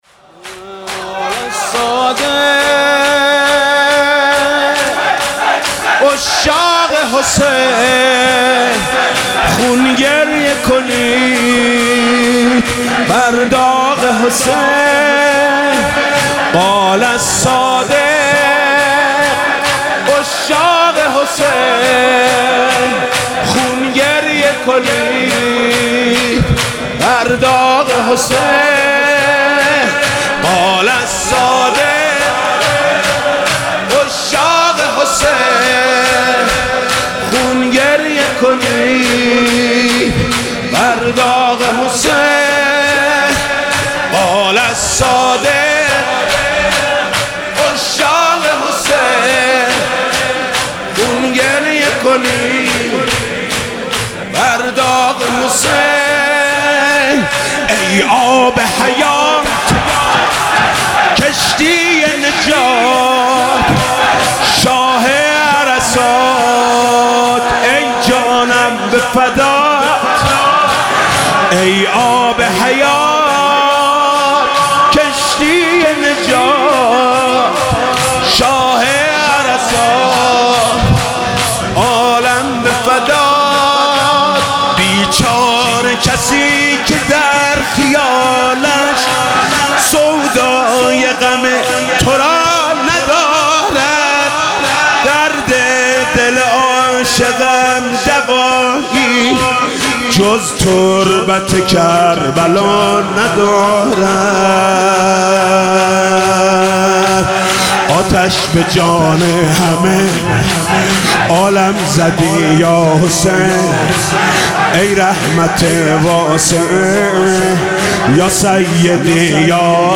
«شهادت امام صادق 1396» شور: قال الصادق عشاق حسین